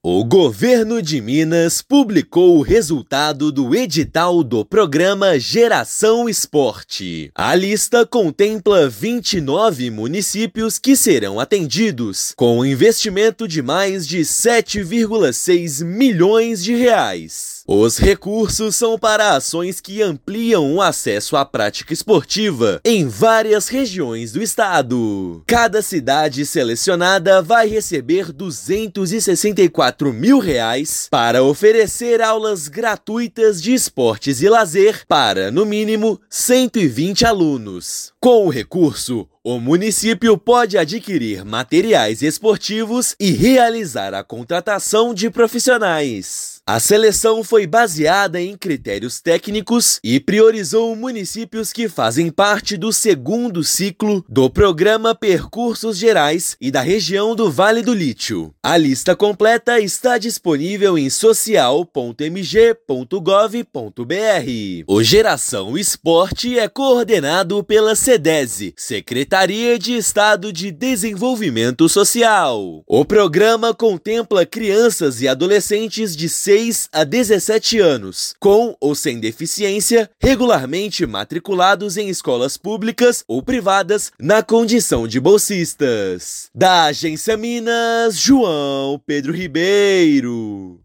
Com investimento de mais de R$ 7,6 milhões, programa da Sedese promove inclusão esportiva de crianças e adolescentes. Ouça matéria de rádio.